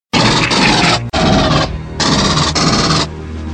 m41a-fire4.wav